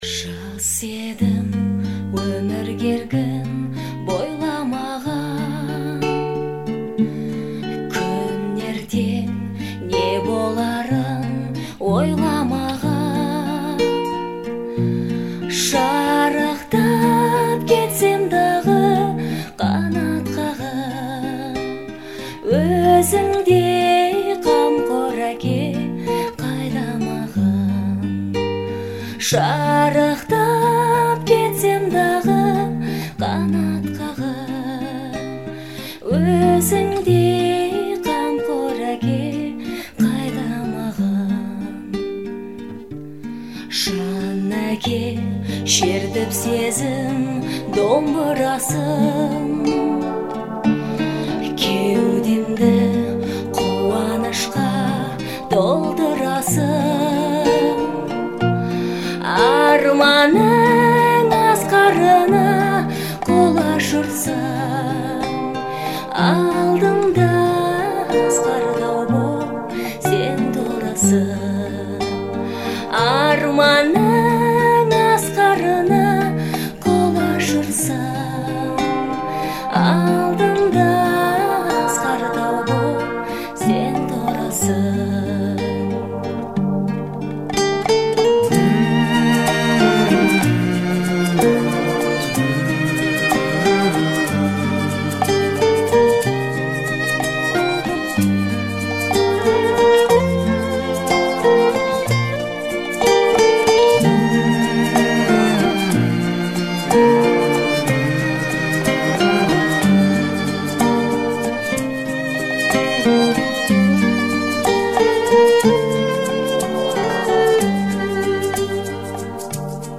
которая относится к жанру казахской народной музыки.